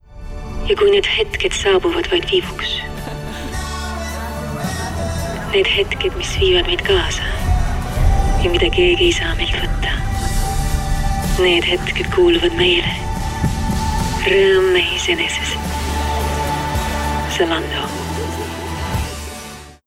Commerciale, Naturelle, Polyvalente, Chaude
Corporate
She speaks English and Estonian as her primary languages with an emphasis on RP, Standard American and Estonian delivered in a warm, seductive and smooth tone.